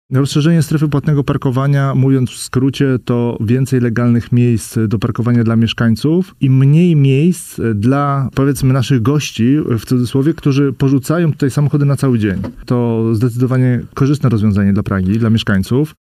Jak przekonywał w „Rundzie Pytań” radny Pragi-Północ Grzegorz Walkiewicz – obszar płatnego parkowania powinien być rozszerzony.